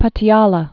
(pŭtēlə)